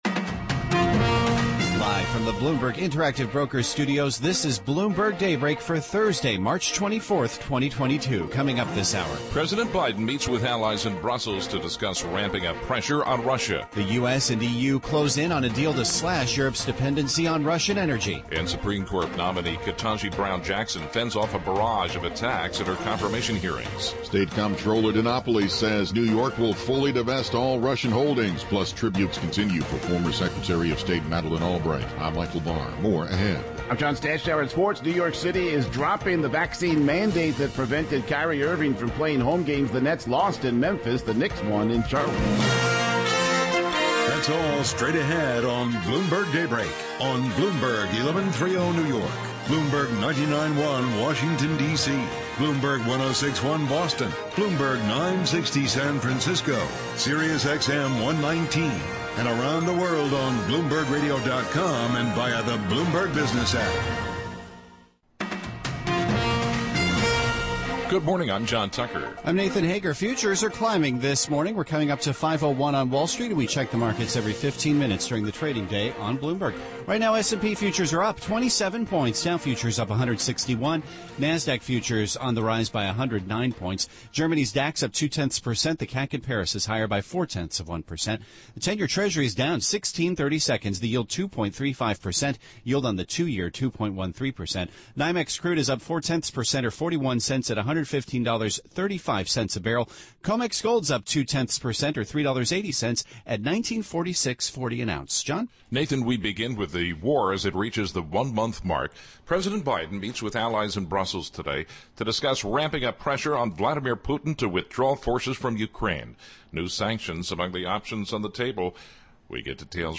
live from San Francisco for the Bloomberg Equality Summit. She will talk economy and monetary policy off the top.